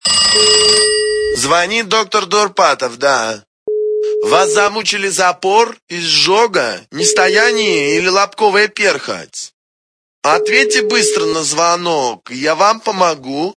» Звуки » Смешные » Звонит - Доктор Дурпатов!
При прослушивании Звонит - Доктор Дурпатов! качество понижено и присутствуют гудки.